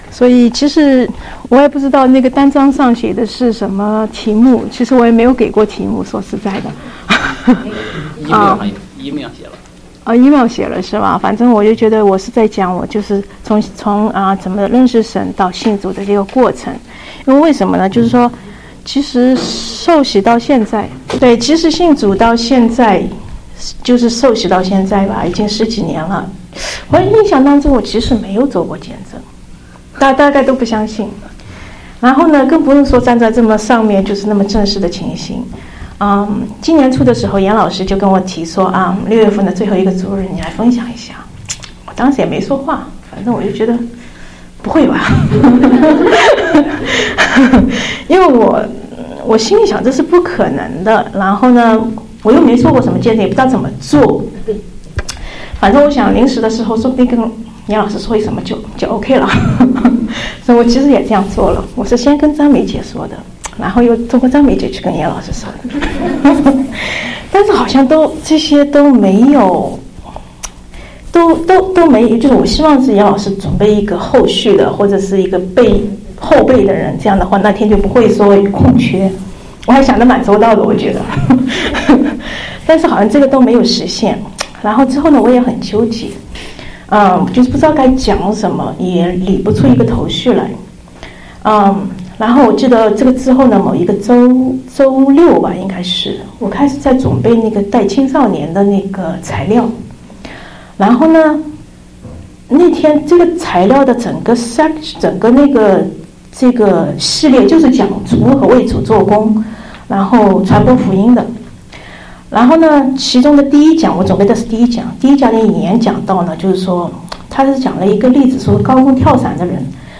見證分享